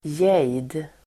Ladda ner uttalet
jade substantiv, jade Uttal: [jej:d el.²ja:de] Böjningar: jaden Definition: en grön halvädelsten Sammansättningar: jade|ring (jade ring) jade substantiv, jade [mineralogi] jade substantiv, jade , grön , jadegrön , ljusgrön